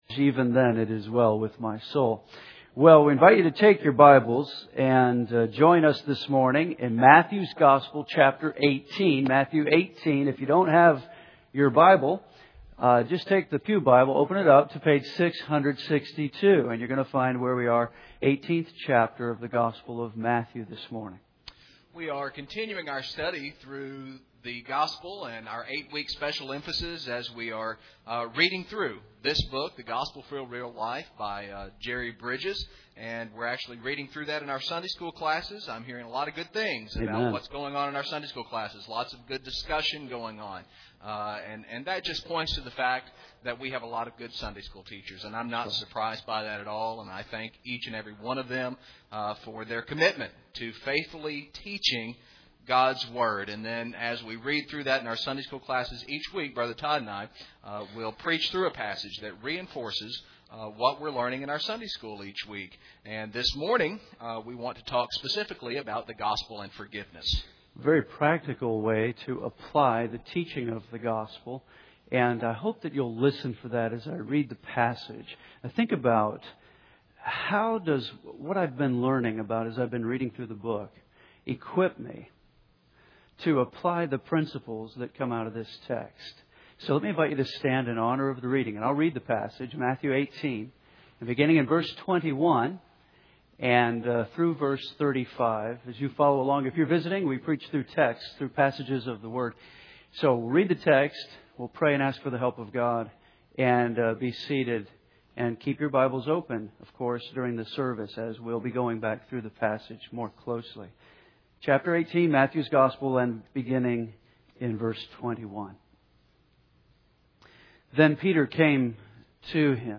Team Preaching